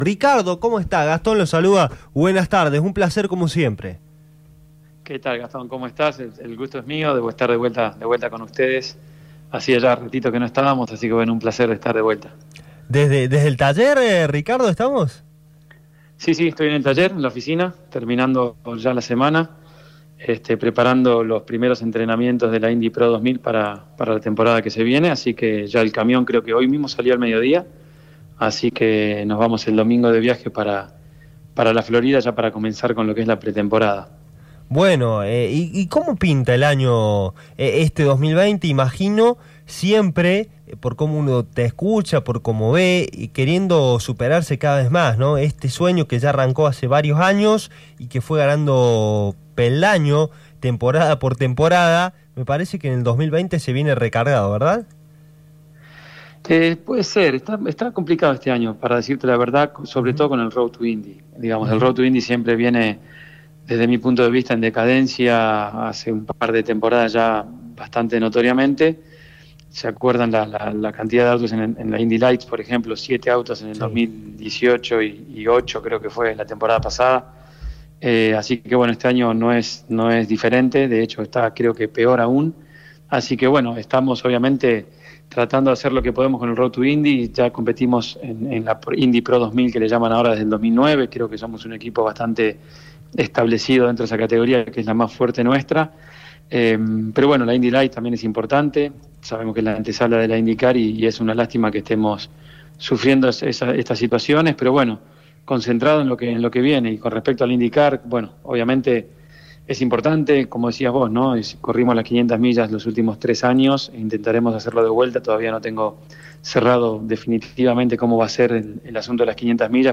En la entrevista